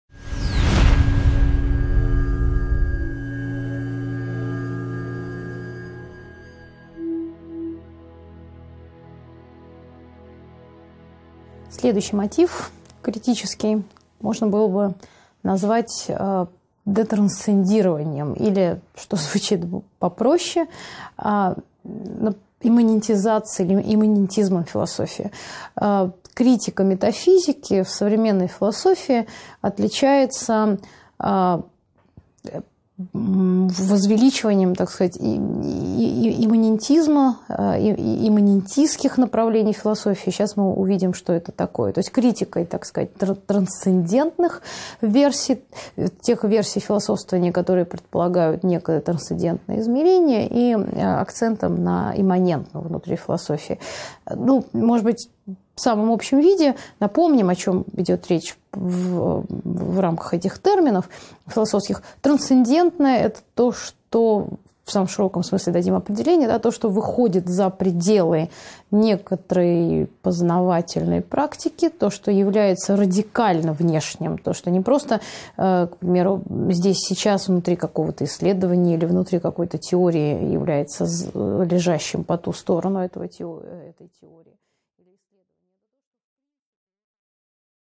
Аудиокнига 14.6 Детрансцендирование и имманентизм в философии | Библиотека аудиокниг